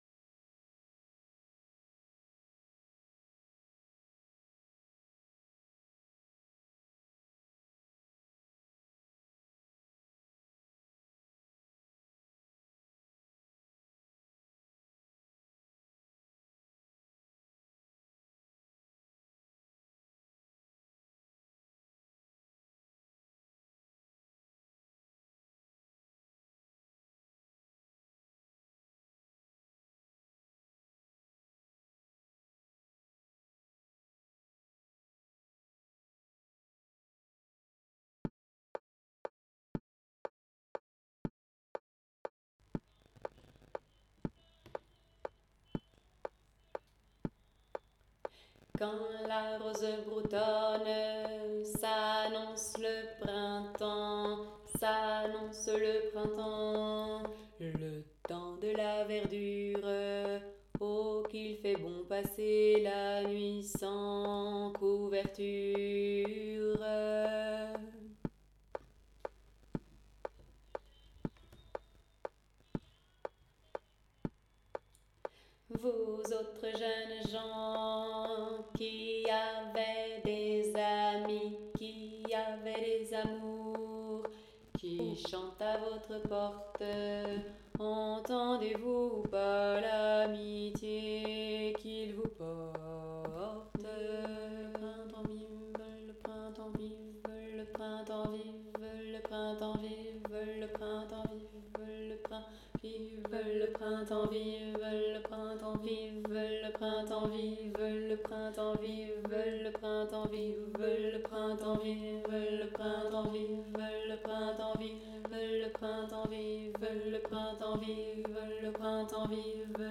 Vous trouverez ci-dessous les trois chants et leurs arrangements pour polyphonie sous forme d'enregistrements mp3 et de partitions pour les différentes voix.
- La couverture - arrangement voix 3